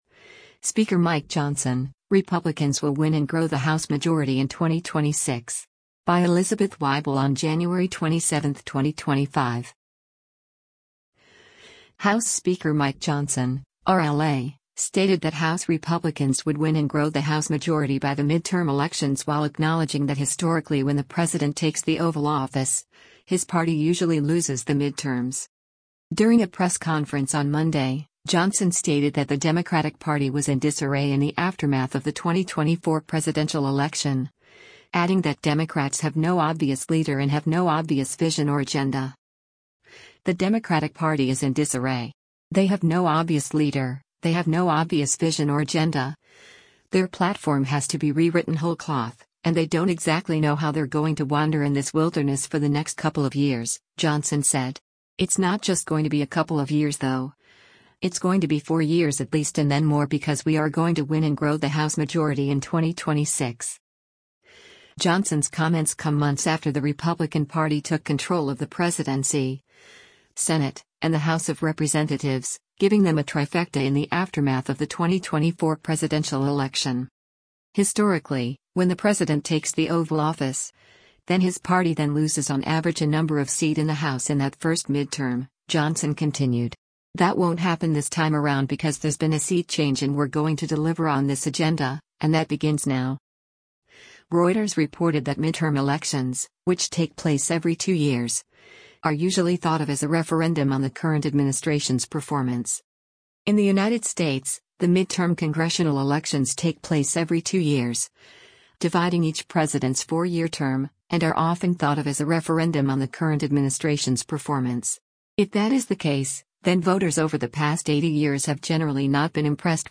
During a press conference on Monday, Johnson stated that the Democratic Party was “in disarray” in the aftermath of the 2024 presidential election, adding that Democrats have “no obvious leader” and “have no obvious vision or agenda.”